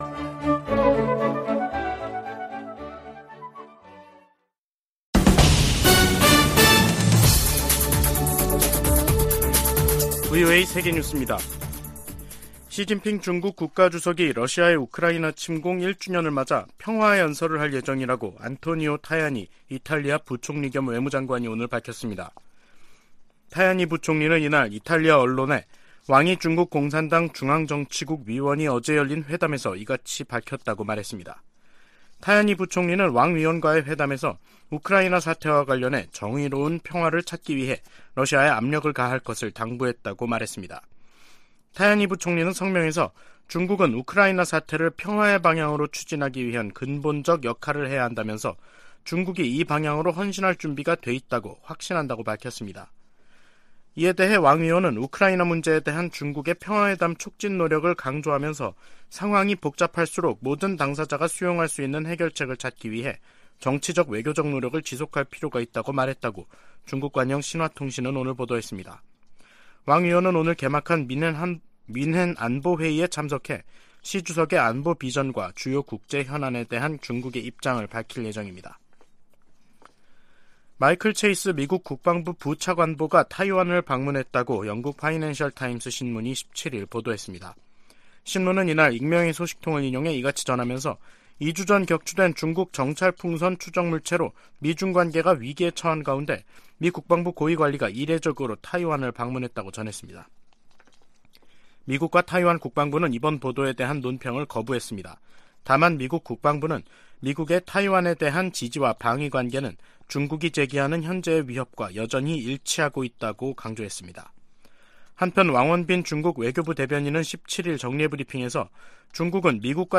VOA 한국어 간판 뉴스 프로그램 '뉴스 투데이', 2023년 2월 17일 3부 방송입니다. 북한의 플루토늄 20kg 증가는 원자로 지속 가동의 결과이며, 올해 6kg 추가가 가능하다고 전 IAEA 사무차장이 밝혔습니다. 러시아 용병 회사 바그너 그룹을 즉각 테러 단체로 지정할 것을 촉구하는 법안이 미 상원에 초당적으로 발의됐습니다. 미국과 한국이 다음달 중순 대규모 야외기동훈련을 포함한 연합훈련을 실시합니다.